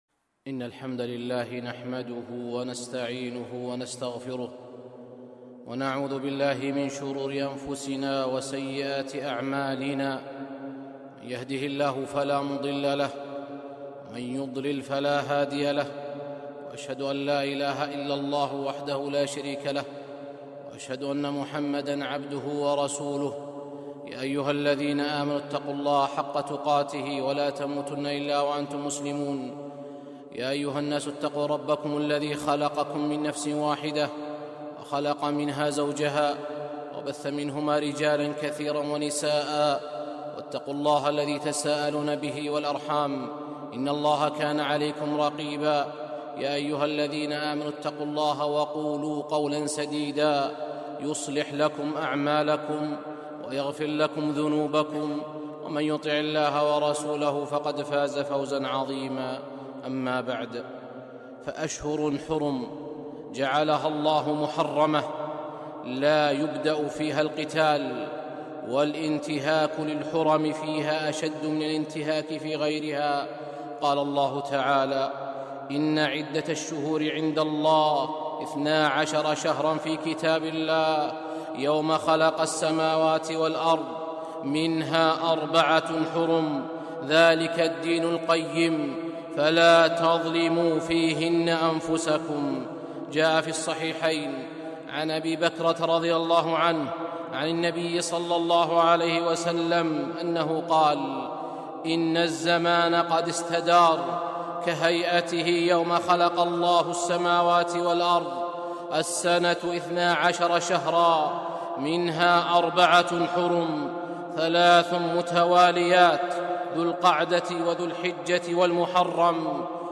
خطبة - مُحدثات في شهر رجب 7-7-1442